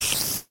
spider